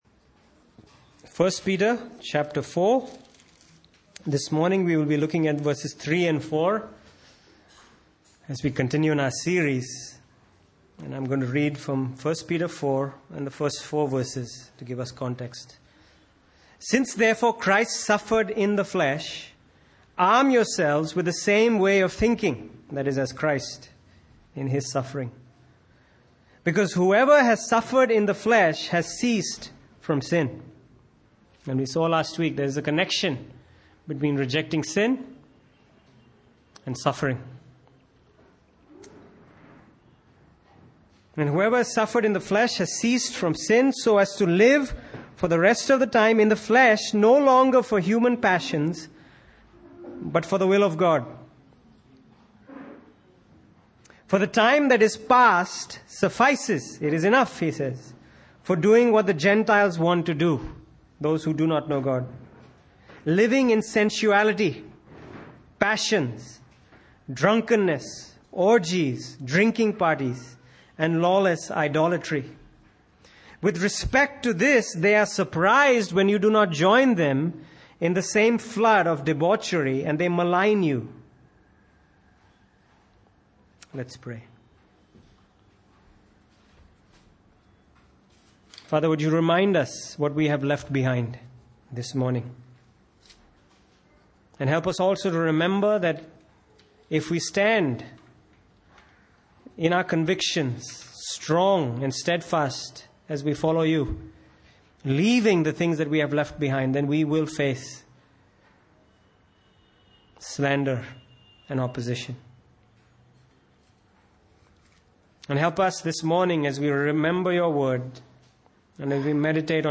1 Peter 4:3-4 Service Type: Sunday Morning « Assurance If You Love the Gospel